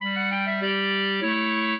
clarinet
minuet14-5.wav